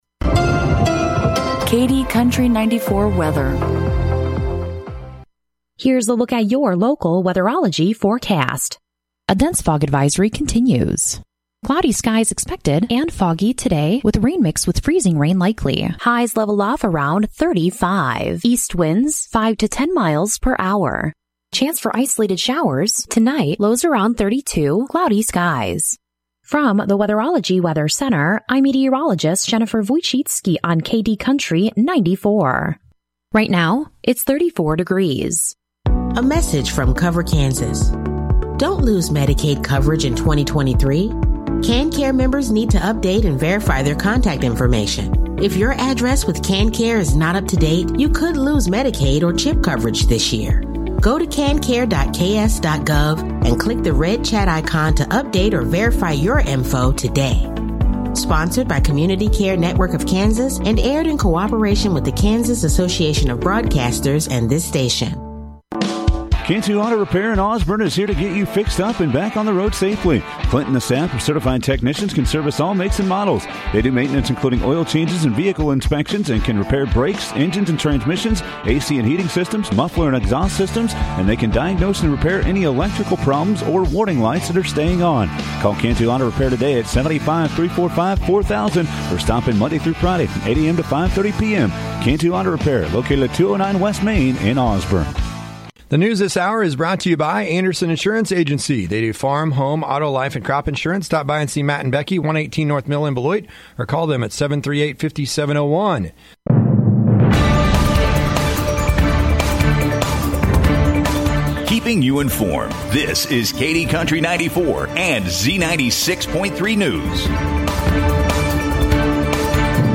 KD Country 94 Local News, Weather & Sports - 1/23/2024